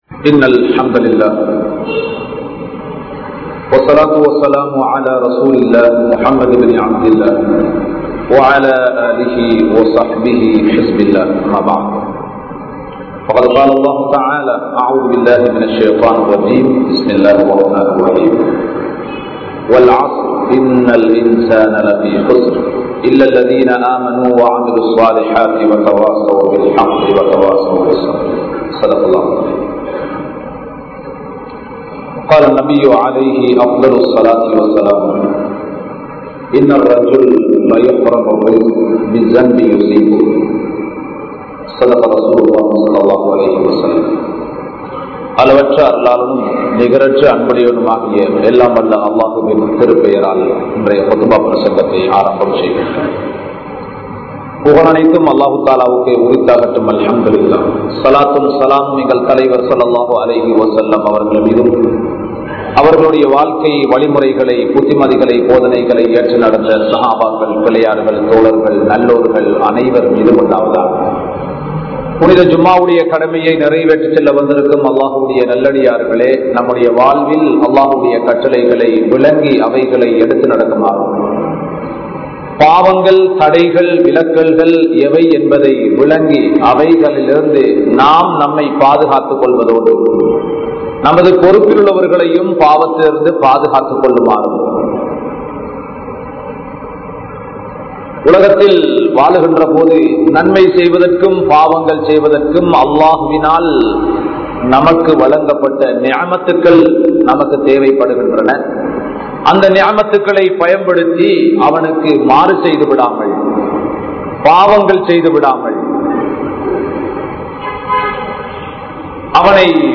Paavathin Rahasiyangal(பாவத்தின் ரகசியங்கள்) | Audio Bayans | All Ceylon Muslim Youth Community | Addalaichenai
Borella Jumua Masjith